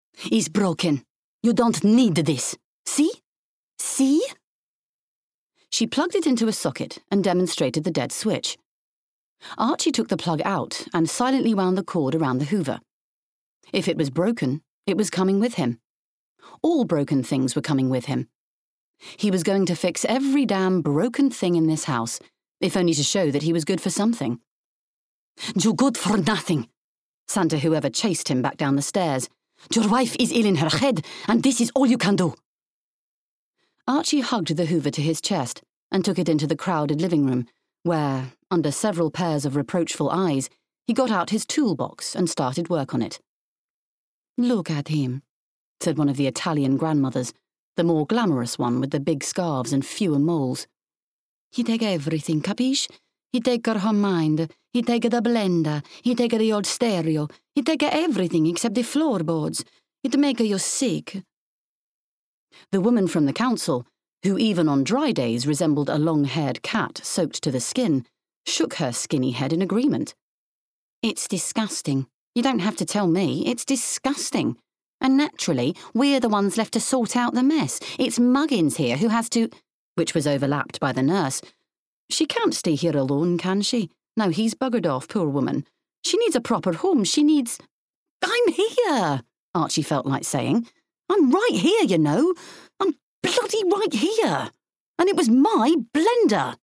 30/40's Neutral/RP,
Calm/Reassuring/Intelligent